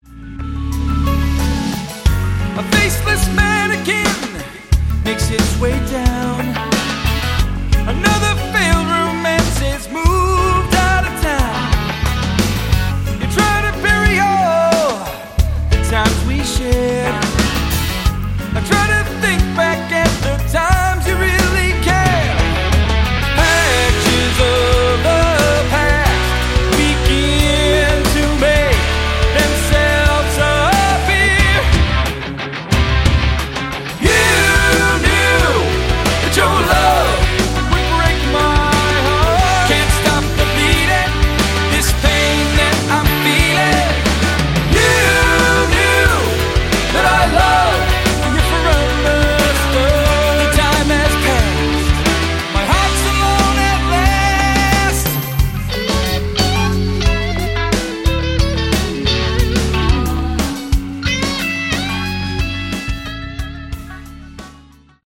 Category: AOR
vocals, guitars
keyboards
synthesizers
bass
drums
guitars